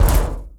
vehicleImpact.wav